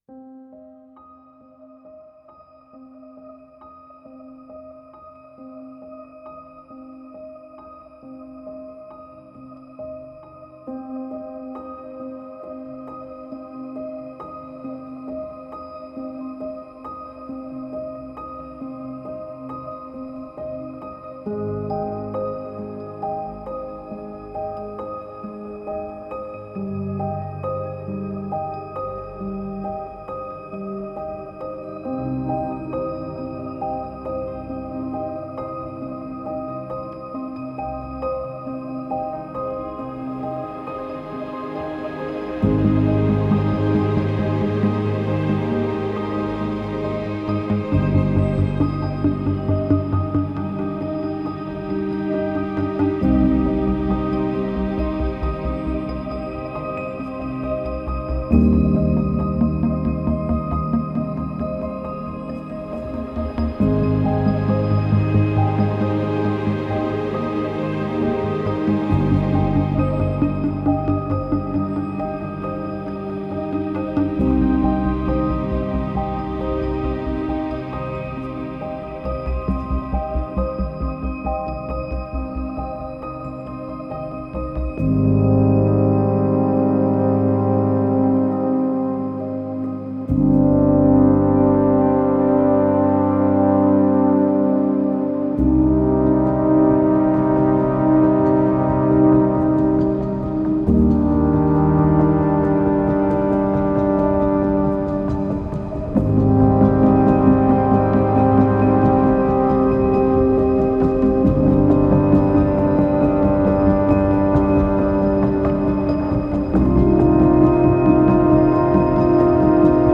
Subtle piano echoes through the inner landscape.